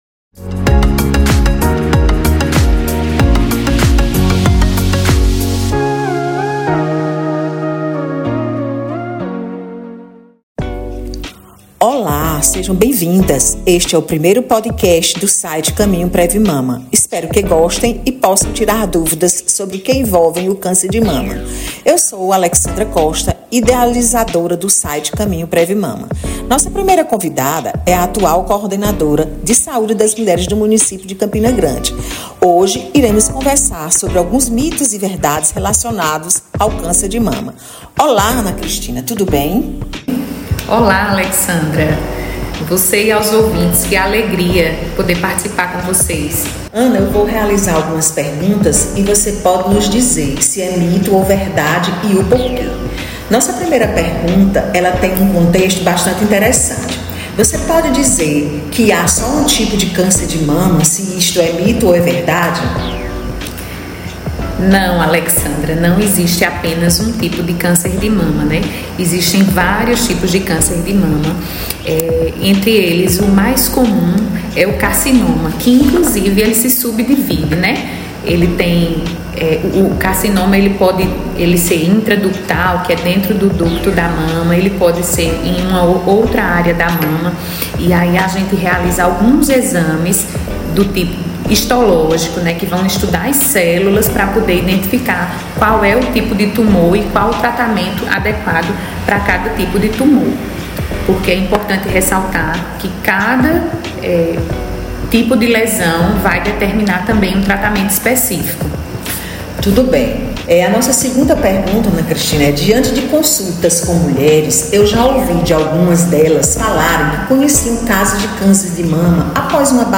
Retirar o eco do podcast para melhor a escuta.